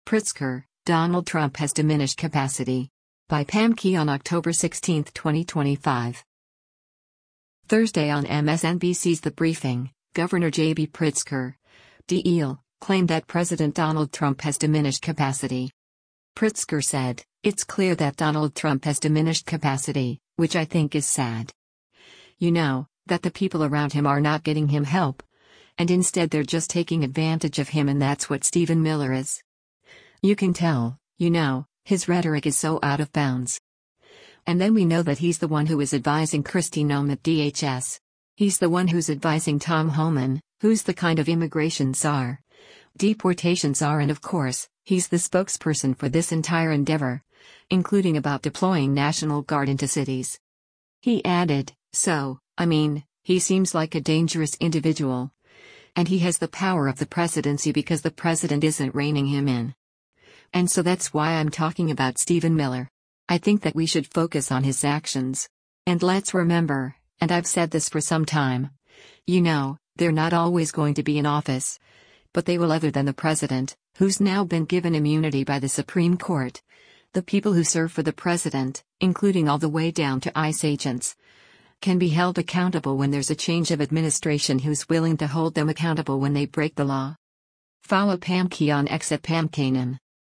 Thursday on MSNBC’s ‘The Briefing,” Gov. JB Pritzker (D-IL) claimed that President Donald Trump has “diminished capacity.”